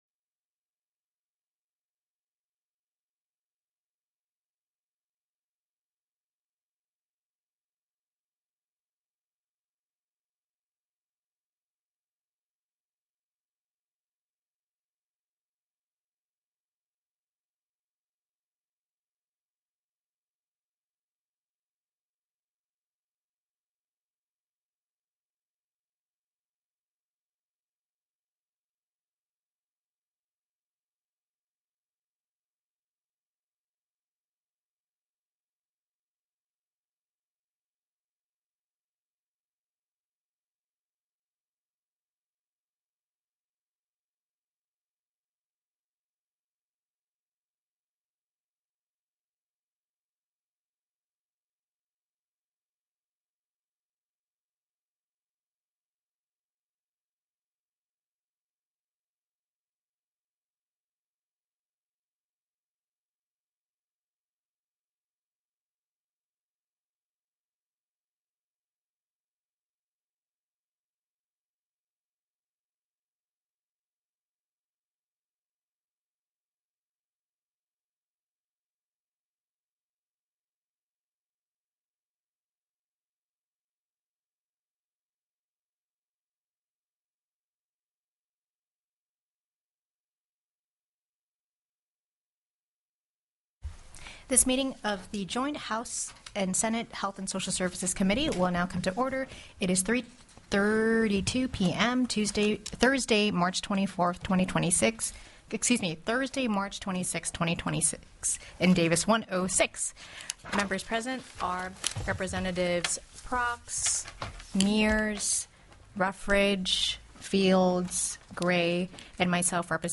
The audio recordings are captured by our records offices as the official record of the meeting and will have more accurate timestamps.
-- Meeting Jointly with House Health & Social Services Committee + Presentation: Alaska Citizens Review Panel